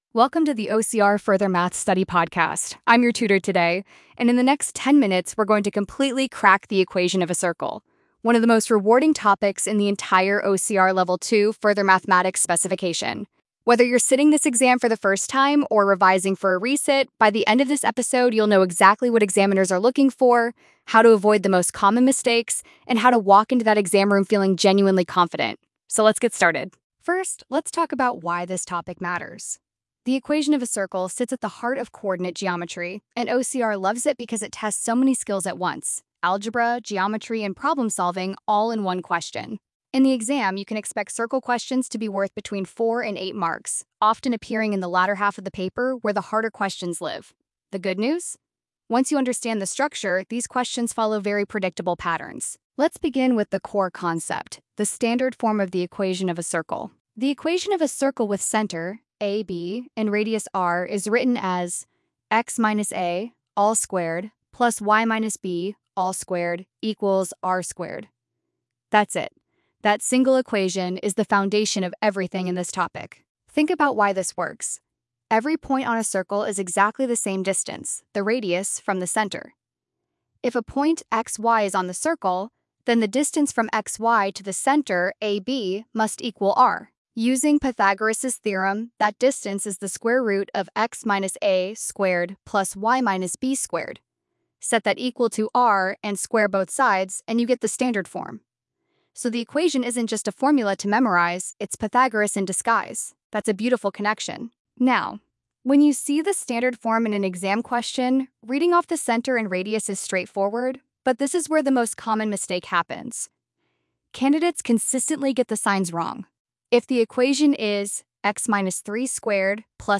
🎙 Podcast Episode Equation of a Circle 11:57 0:00 -11:57 1x Show Transcript Study Notes Overview The Equation of a Circle is a cornerstone of the Coordinate Geometry section in OCR's Level 2 Further Mathematics.
equation_of_a_circle_podcast.mp3